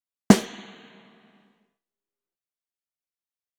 Federhall
Soundbeispiel Snare
Federhall zeichnet sich durch deutlich wahrnehmbare, diskrete Reflexionen aus.
snareampspring.wav